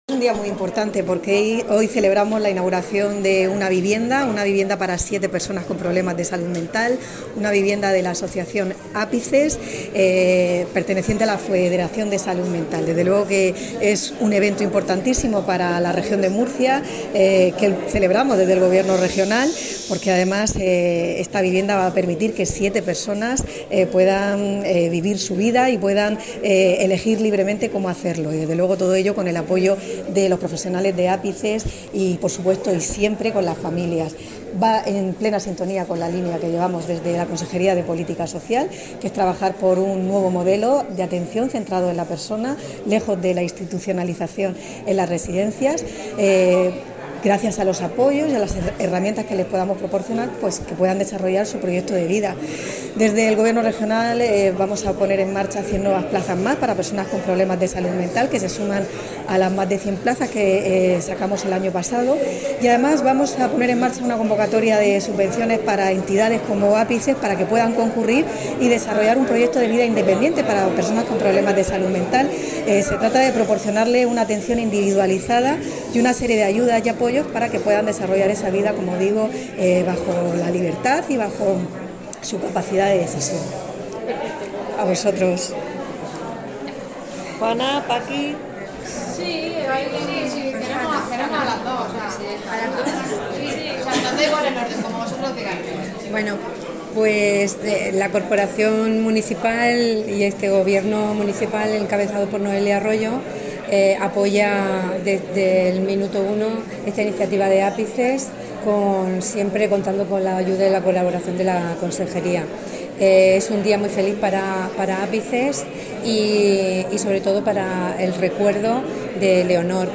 Enlace a Declaraciones de Concepción Ruiz
durante la inauguración de la vivienda tutelada de APICES